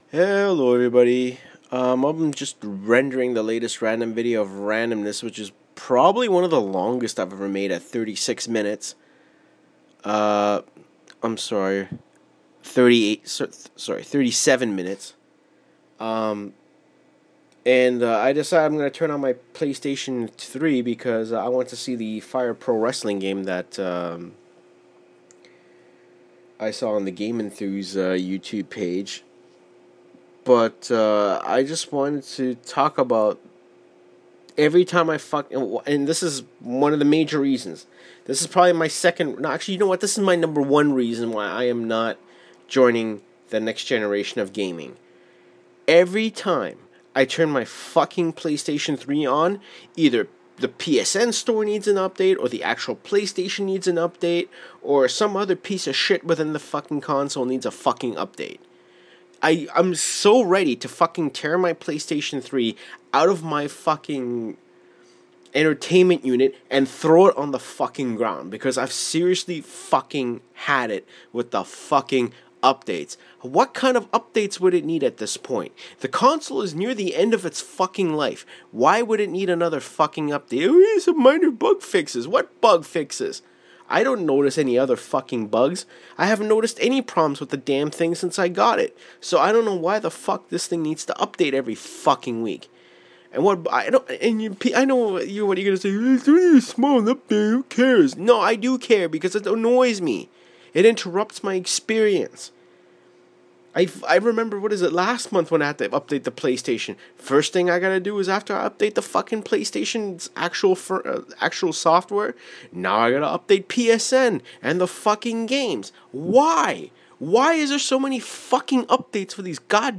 Rant: updates